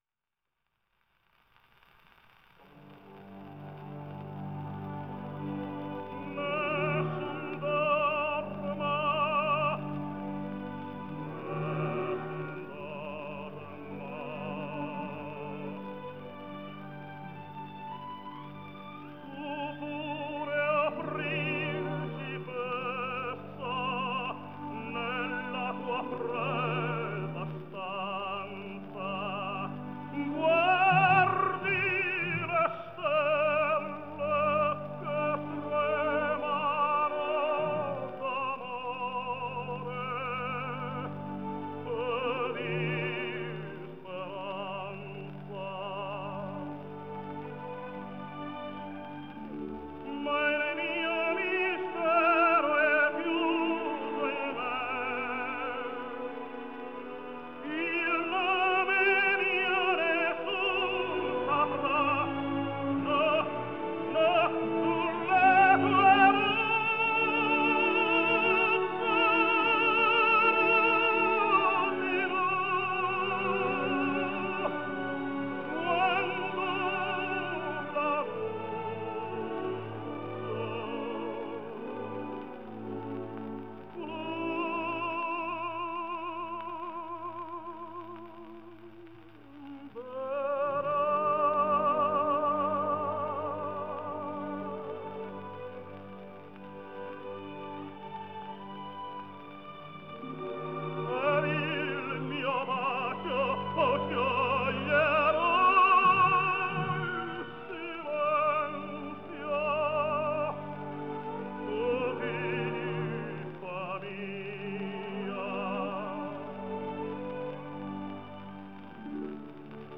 Антонио Кортис (Антонио Томас Монтон Кортс; 1891 - 1952) - испанский певец (тенор).